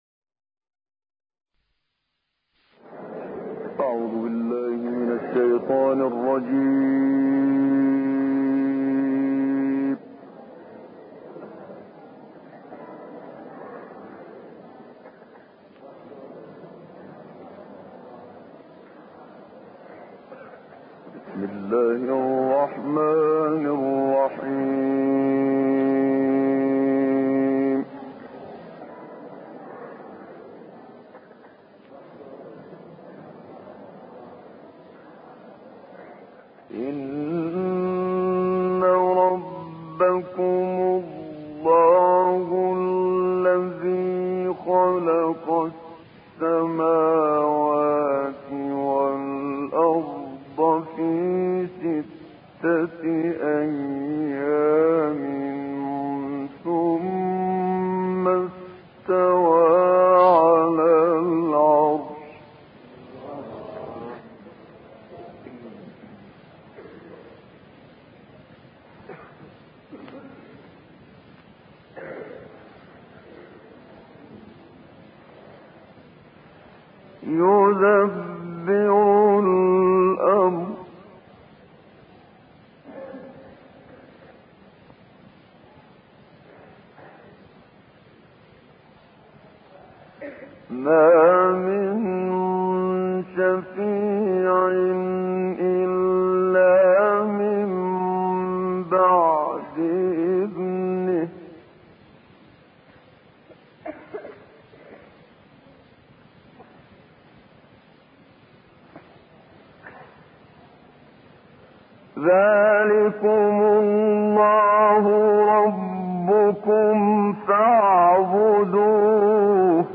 مسجد الاقصی